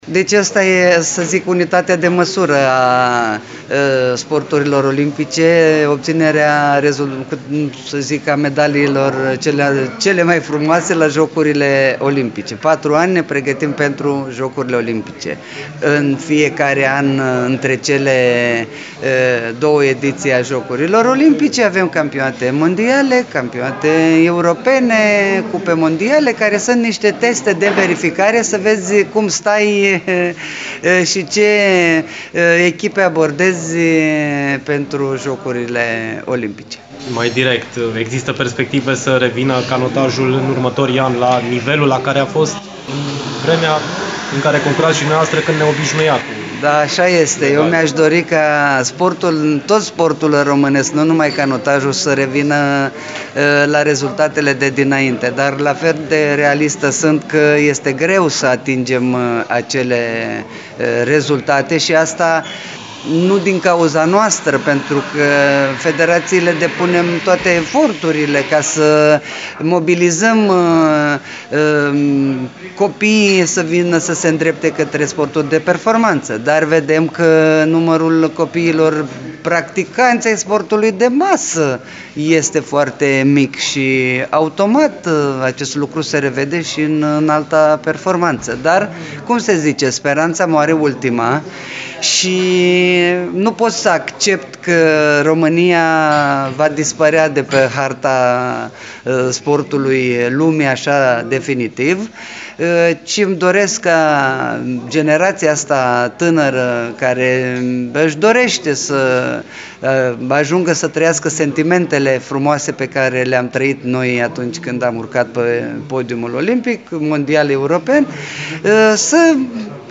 Pentru final, ascultăm concluziile președintelui Federației Române de Canotaj, Elisabeta Lipă: